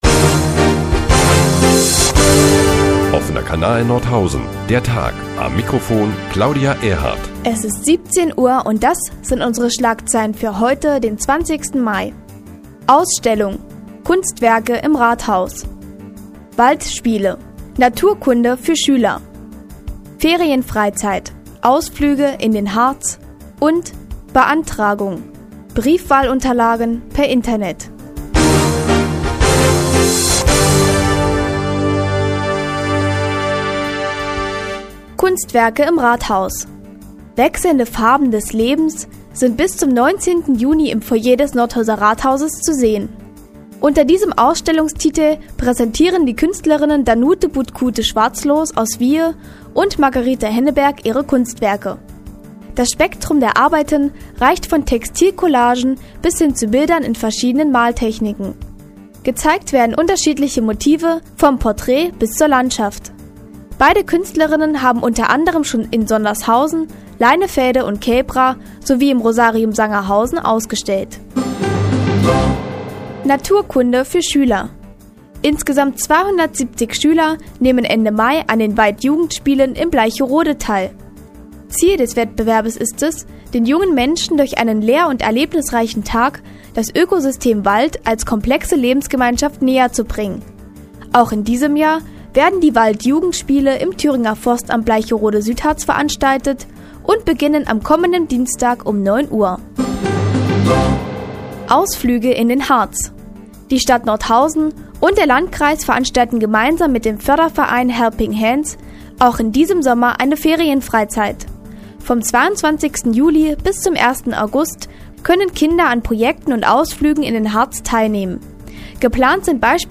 Die tägliche Nachrichtensendung des OKN ist nun auch in der nnz zu hören. Heute unter anderem mit Kunstwerken im Rathaus und Briefwahlunterlagen im Internet.